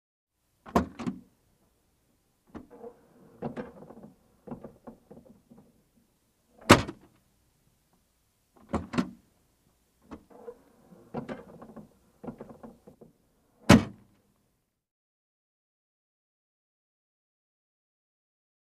Mercedes-Benz|Trunk O/C | Sneak On The Lot
Car Trunk Open And Close; Mercedes Close Perspective.